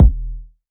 small_sub_kick.wav